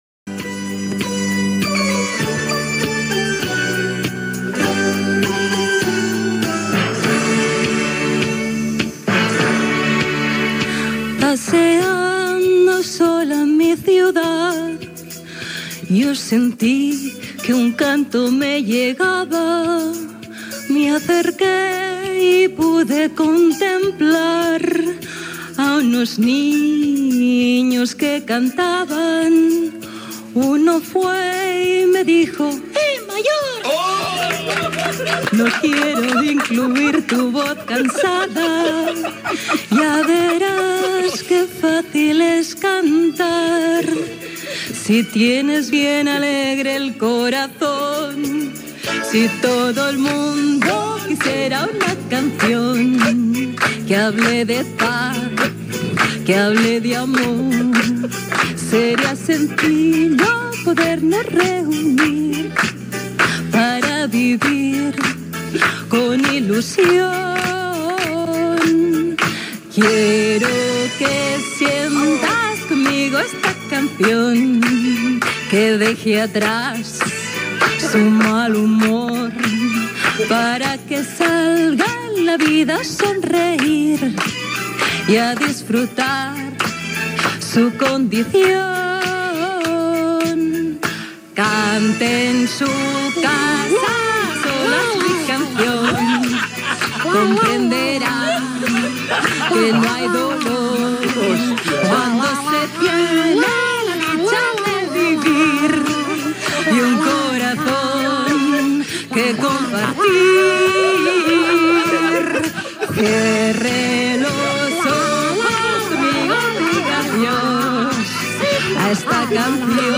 Entreteniment
fa la veueta de nen.